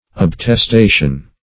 Search Result for " obtestation" : The Collaborative International Dictionary of English v.0.48: Obtestation \Ob`tes*ta"tion\, n. [L. obtestatio.]